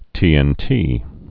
(tēĕn-tē)